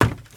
High Quality Footsteps
STEPS Wood, Creaky, Run 26.wav